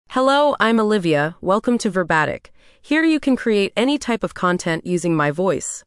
FemaleEnglish (United States)
Olivia is a female AI voice for English (United States).
Voice sample
Female
Olivia delivers clear pronunciation with authentic United States English intonation, making your content sound professionally produced.